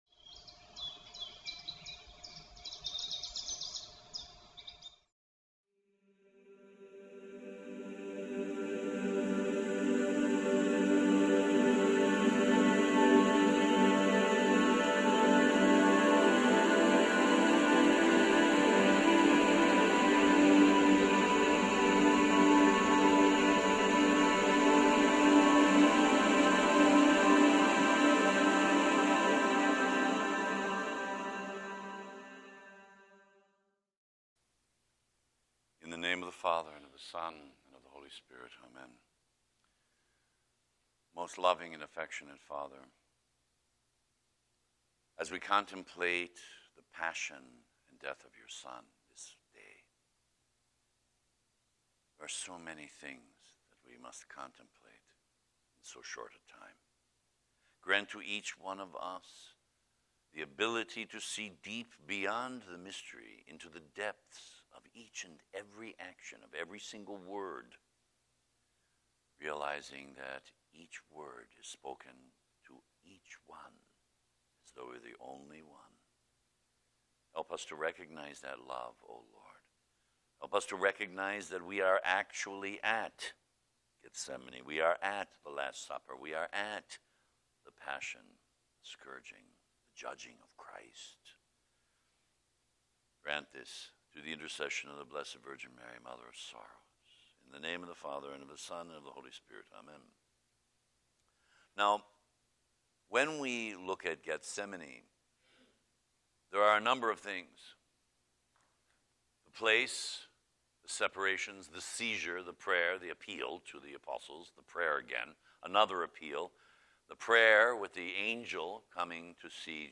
8 Day Retreat